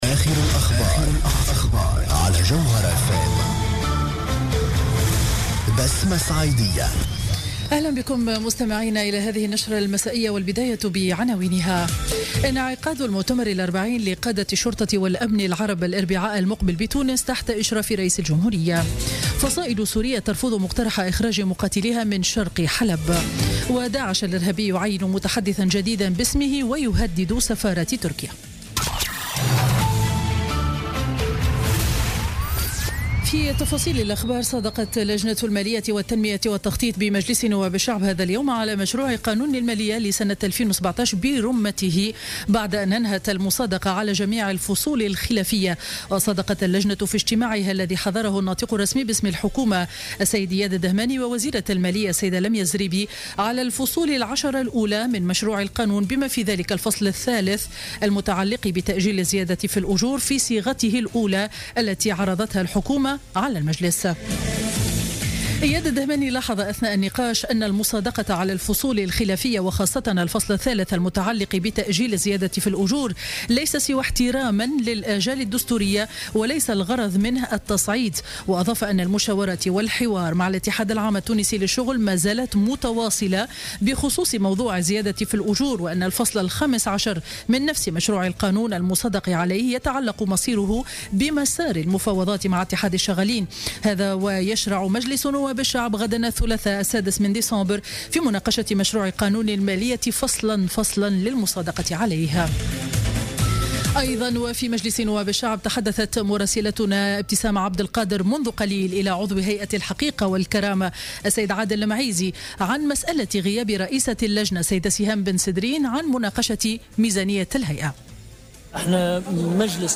نشرة أخبار السابعة مساء ليوم الاثنين 5 ديسمبر 2016